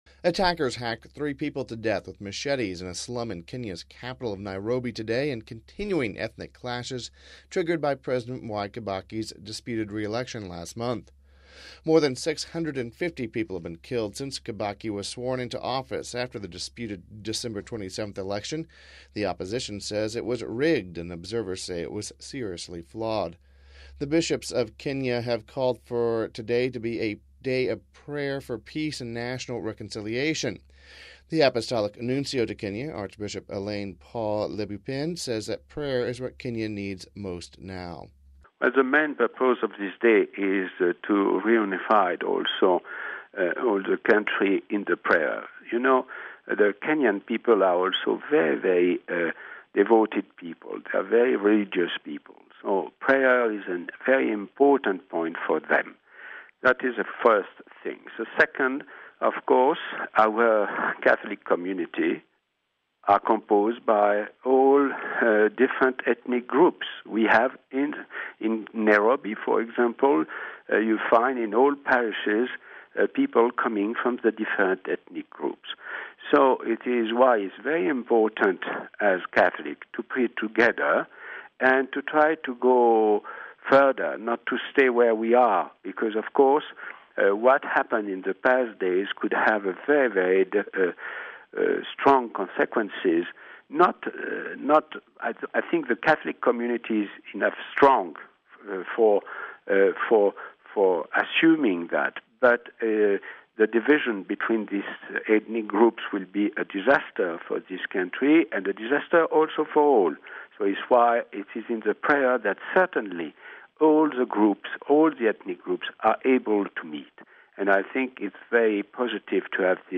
Home Archivio 2008-01-21 08:17:24 Kenya Observes Day of Prayer (20 Jan 08 - RV) Sunday was declared a day of prayer by the bishops of Kenya. We spoke to the Apostolic Nuncio to Kenya, Archbishop Alain Paul Lebeaupin...